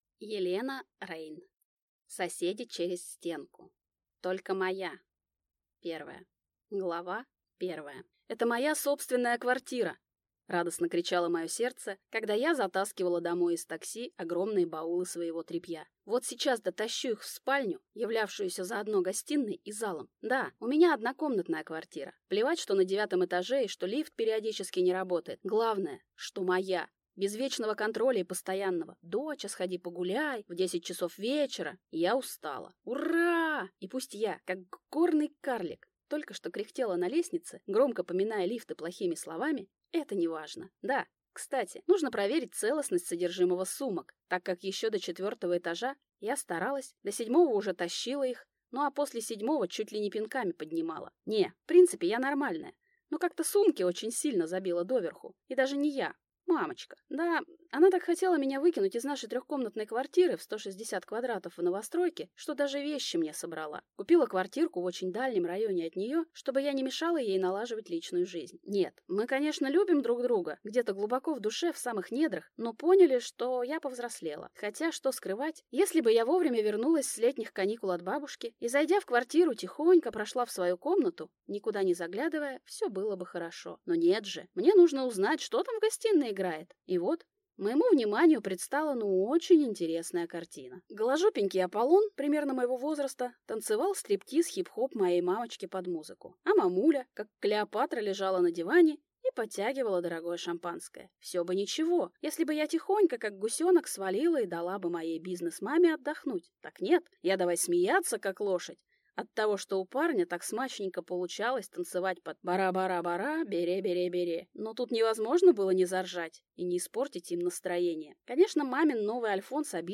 Аудиокнига Соседи через стенку | Библиотека аудиокниг
Прослушать и бесплатно скачать фрагмент аудиокниги